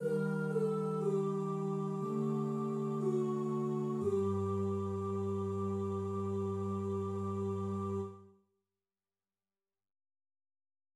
Clausula vera в трёх голосах из Magnificat Secundi Toni: Deposuit potentes Палестрины, такты 27–28[28].
В трёхголосии третий голос часто добавляет нисходящую квинту, создавая каденцию, схожую с аутентической в тональной музыке[28].